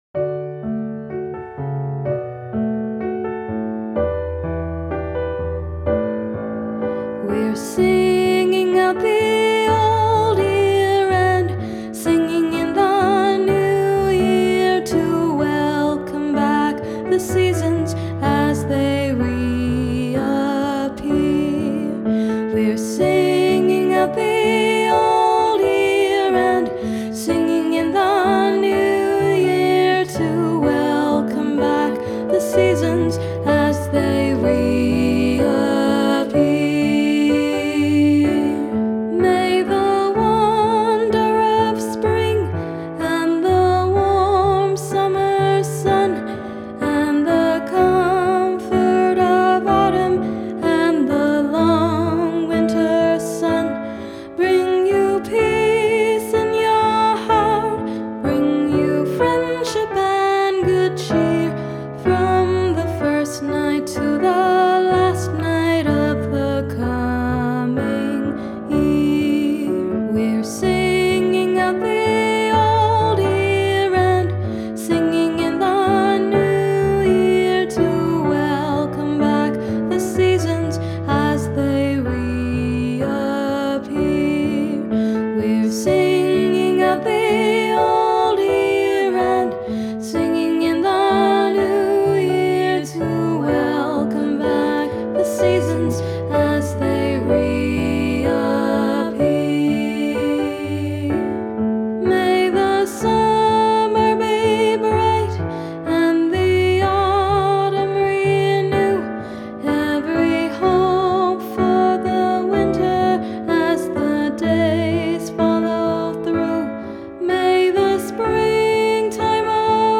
Unison, Multi-Part Song, 2 Parts